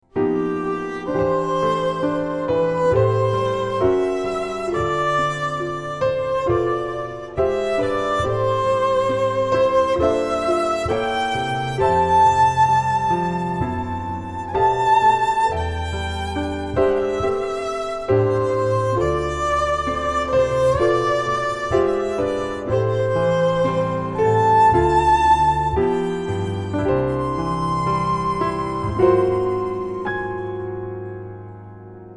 Genre : Ambient, New Age